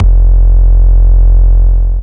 Index of /Antidote Advent/Drums - 808 Kicks
808 Kicks 11 F.wav